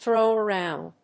thrów aróund